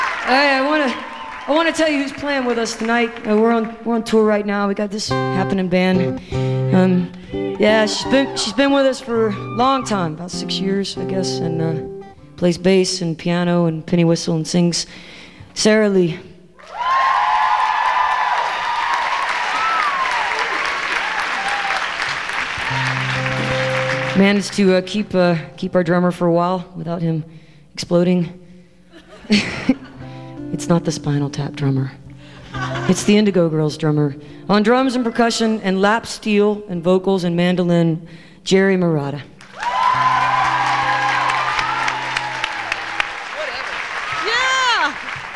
14. band intro (0:45)